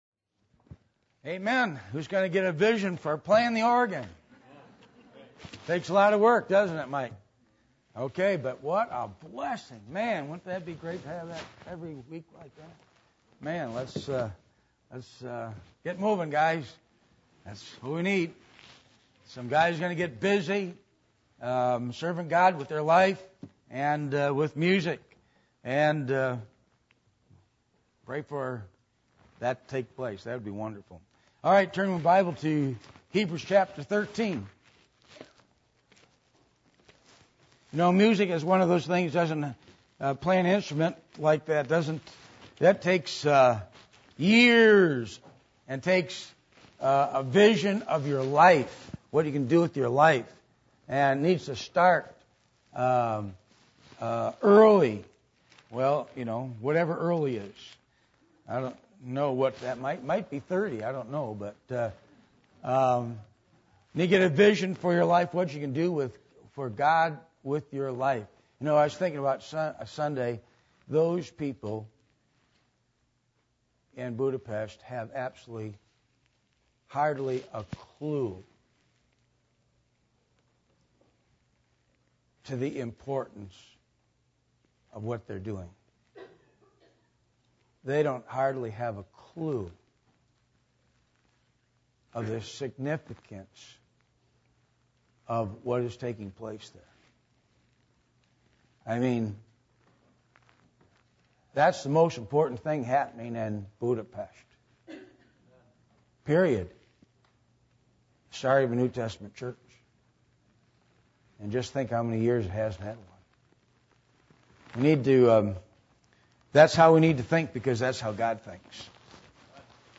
Hebrews 13:5-6 Service Type: Midweek Meeting %todo_render% « Rebellion As The Sin Of Witchcraft The Attributes Of God